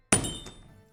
DropSmallMetal.ogg